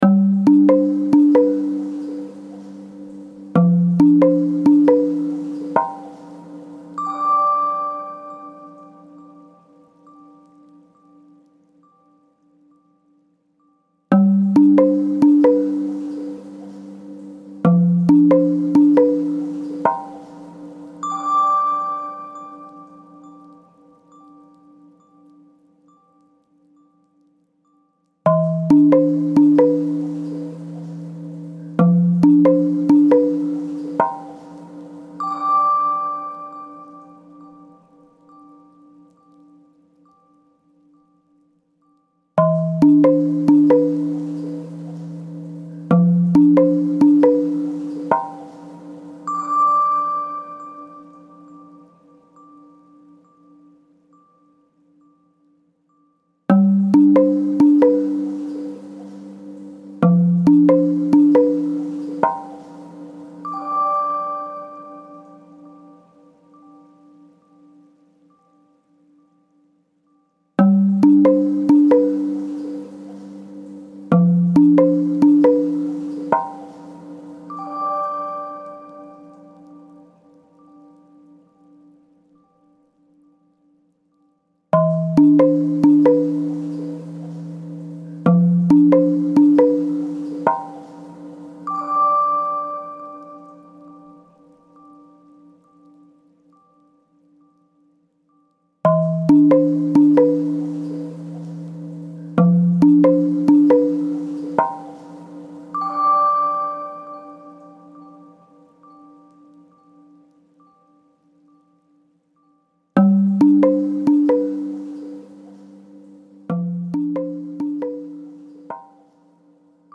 オープンワールド向けにメロディーを最小限に抑えました
南国や温泉風のなんだかちょっぴり温かい気持ちになれる曲です